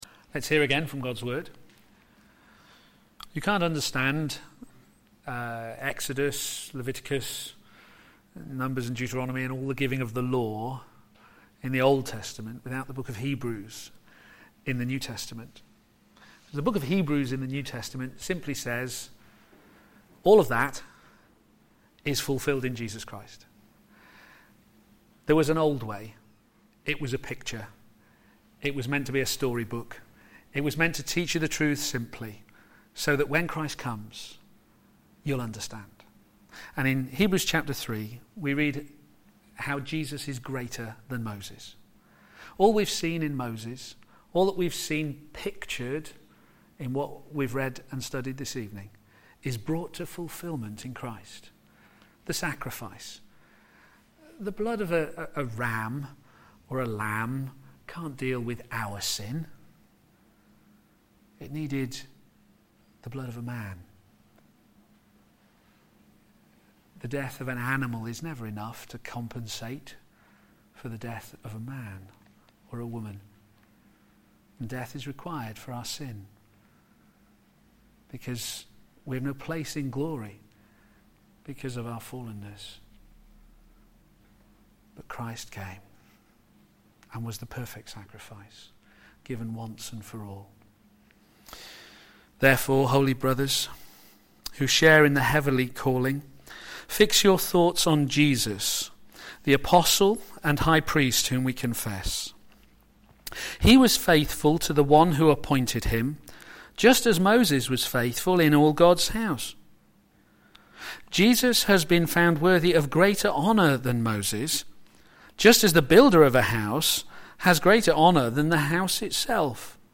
Passage: Exodus 18 Series: True Pilgrimage? Theme: - a picture to the world Sermon In the search box below, you can search for recordings of past sermons.